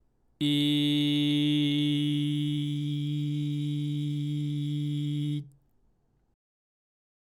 ※仮声帯のジリジリが入ったG(い)⇒笑いながらCに変えていくの音声
10_kaseitai_hanasu_i.mp3